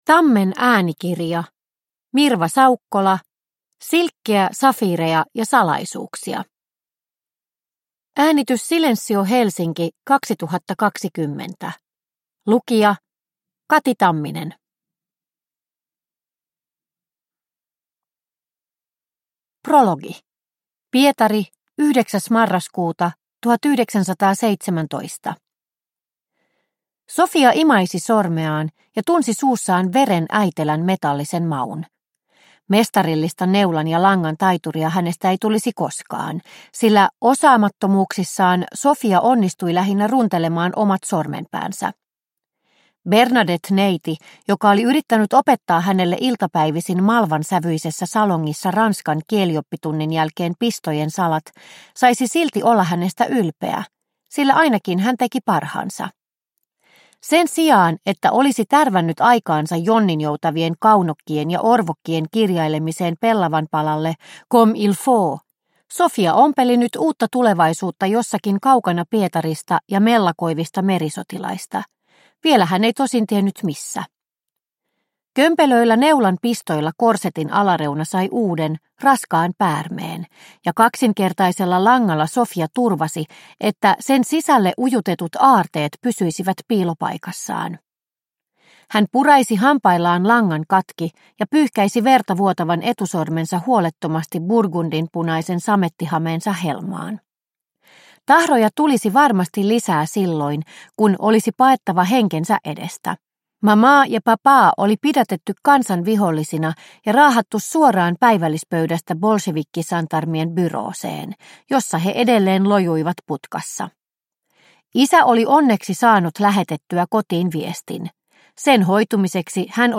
Silkkiä, safiireja ja salaisuuksia – Ljudbok – Laddas ner